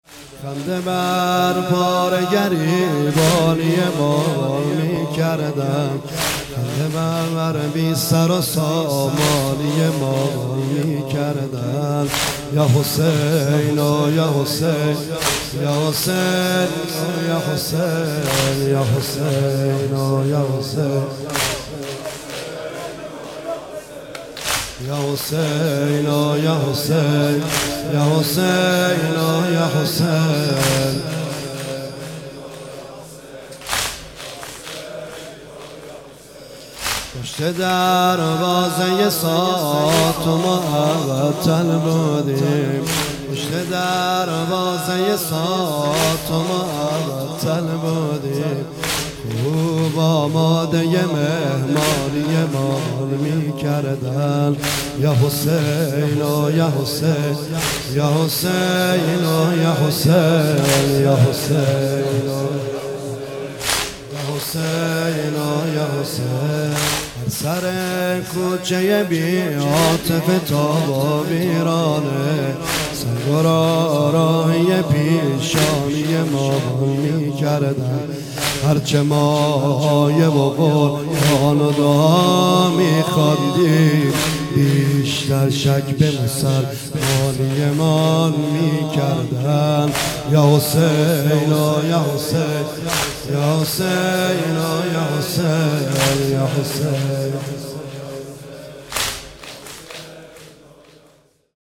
مداحی نریمان پناهی | محرم 1399 | هیئت عشاق الرضا (ع) تهران | پلان 3